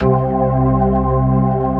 Index of /90_sSampleCDs/AKAI S6000 CD-ROM - Volume 1/VOCAL_ORGAN/POWER_ORGAN
P-ORG2  C2-S.WAV